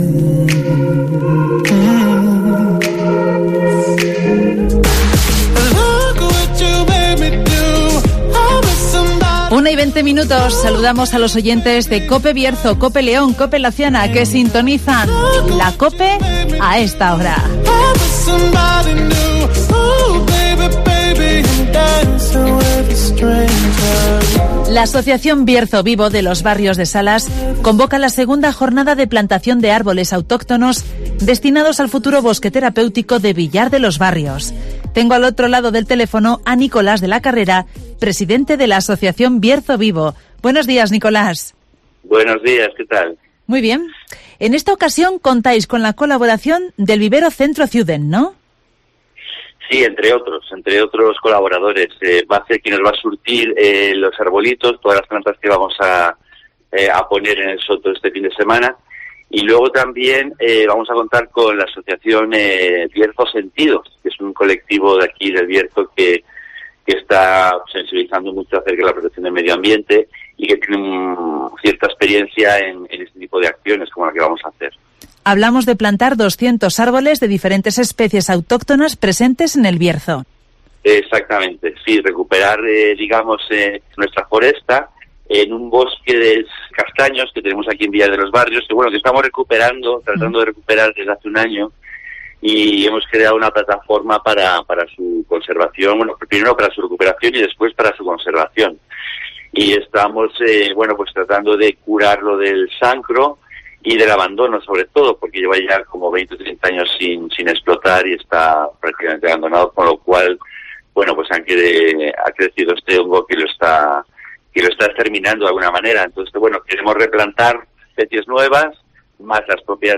Segunda jornada de plantación de árboles autóctonos en Villar de Los Barrios con Bierzo Vivo (Entrevista